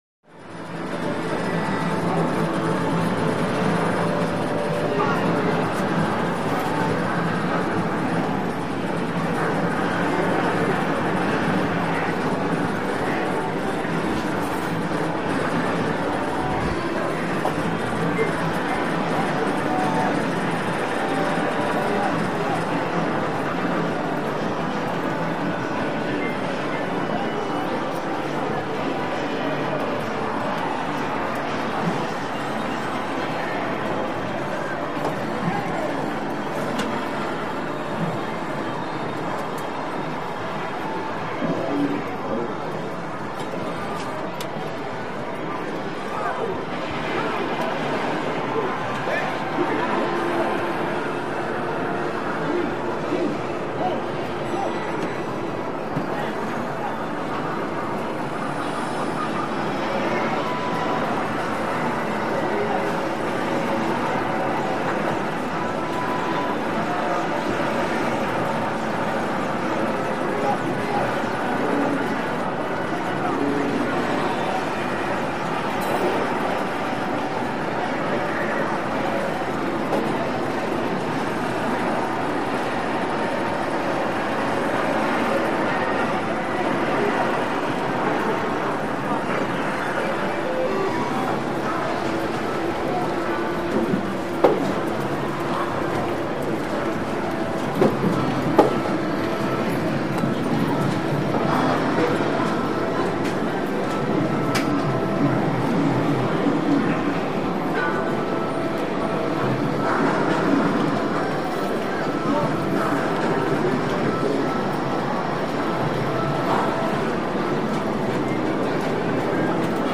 Video Arcade Room Ambience; Close Noisy Electronic Game Sounds With Walla Roar, Clunks And Clatter Of Older Machines, Distant Music.